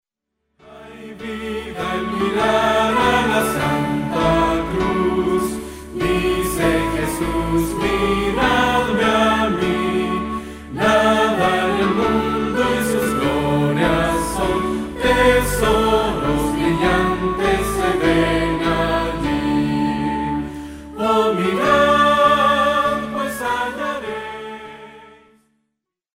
que presenta himnos tradicionales con un enfoque fresco